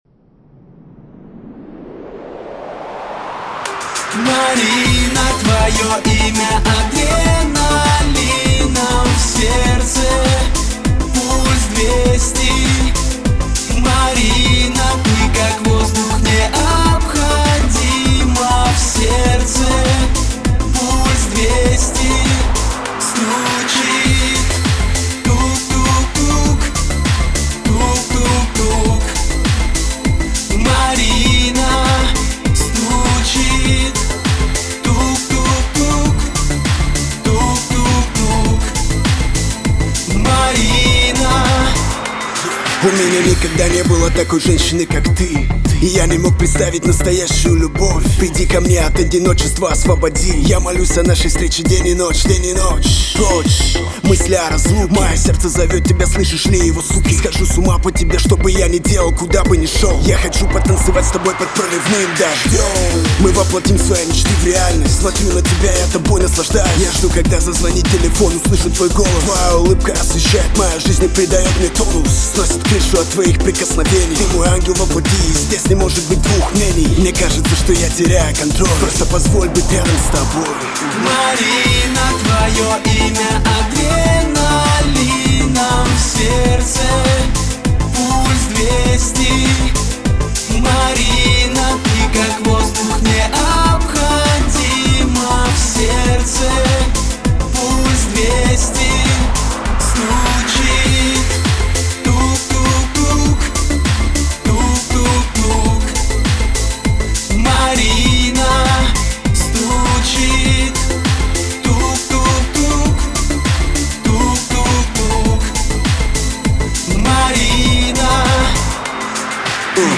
Категория: Русский рэп 2016